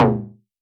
RDM_Copicat_SY1-Perc02.wav